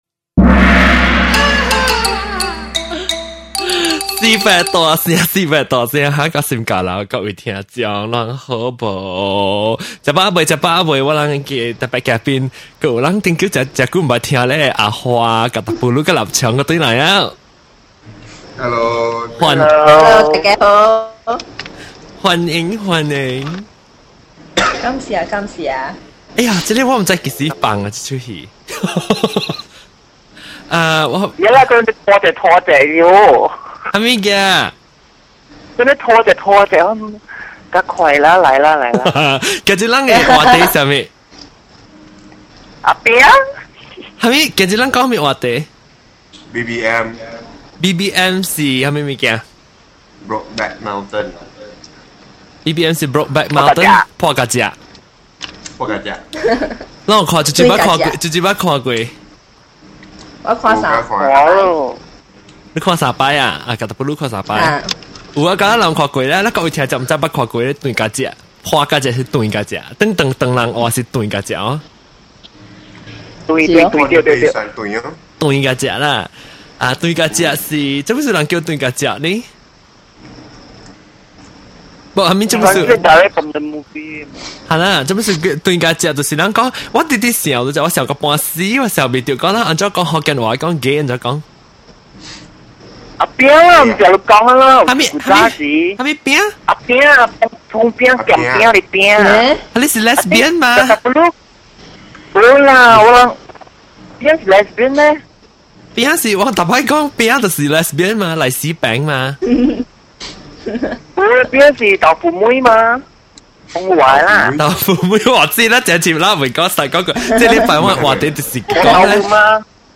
You get to hear directly from two gay men, one straight man and one straight woman’s point of view on the GLBT community.